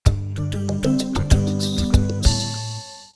01 Power On.wav